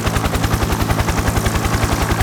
propellers.wav